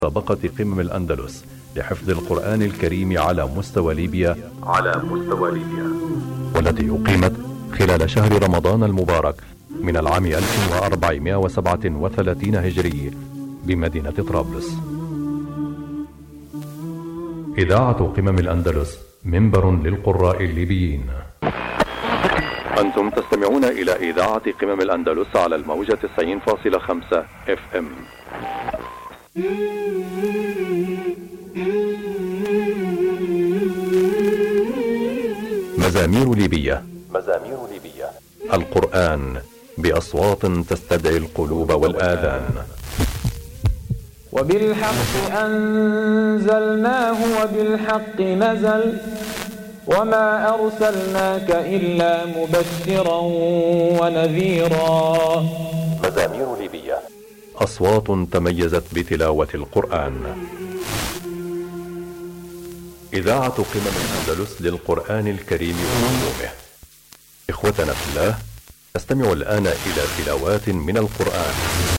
Egy mai felvételem kifogott rajtam...90,5 MHz
- elhangzik Al Quran al Kareem + madina Tarabulus = Tripoli város..."Idaat al feel al endelous" - ez lenne a neve, nem találok róla semmit...szerintem csak rosszul betűzöm :D